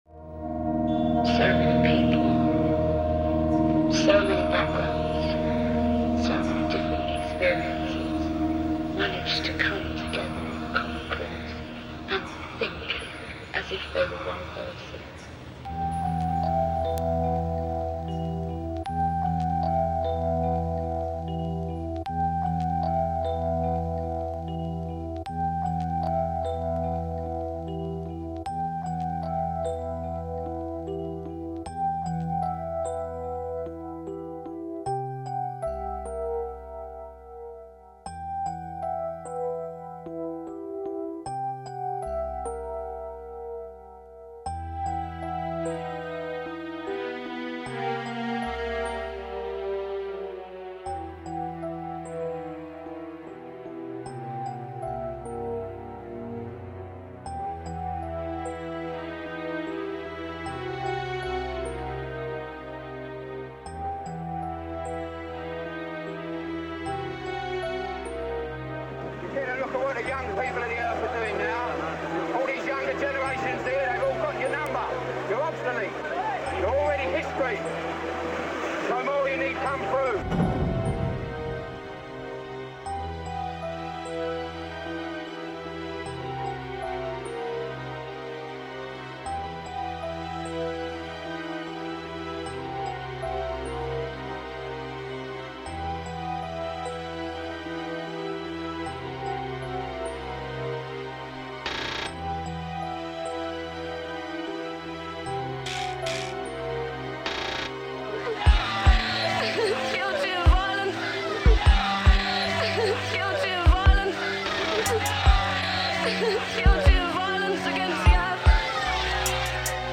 Tout un panaché de couleur sonore et de rythmes....